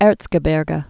(ĕrtsgə-bîrgə)